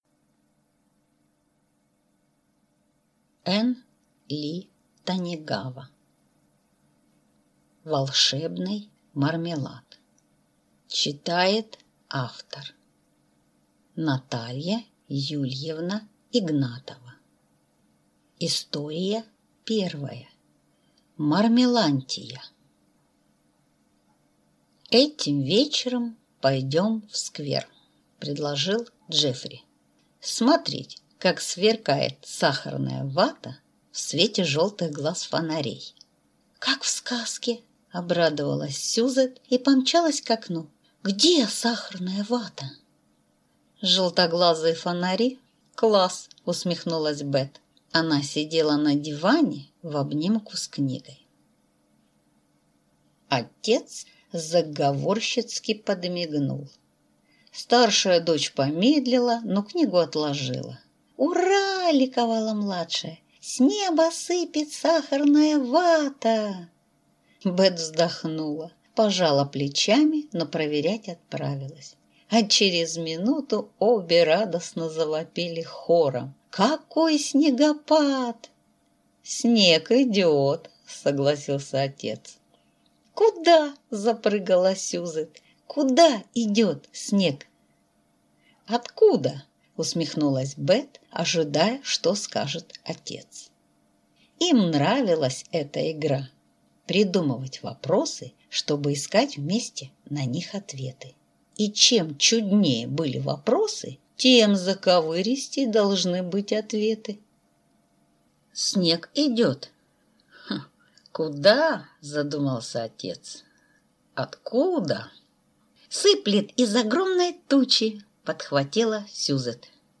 Аудиокнига Мармелантия | Библиотека аудиокниг